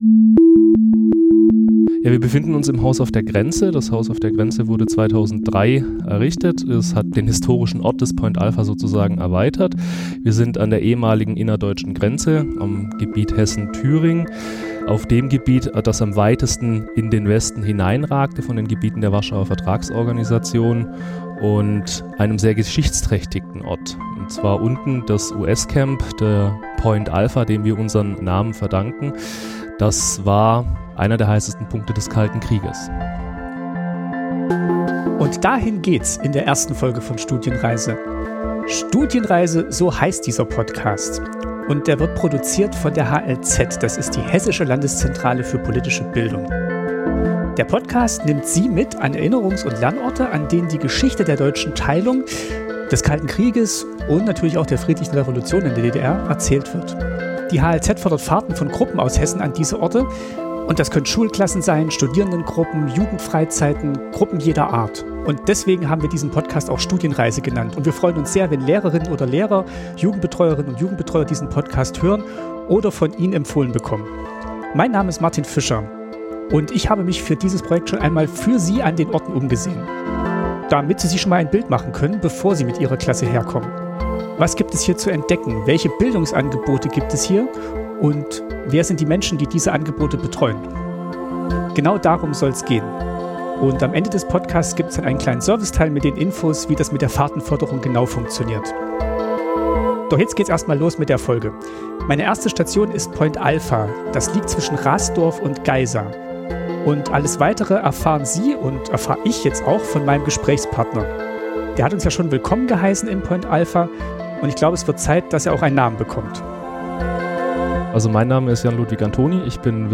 Er nimmt uns mit auf einen Rundgang durch das blaue „Haus auf der Grenze“, die Außenanlagen und das ehemalige US-Camp.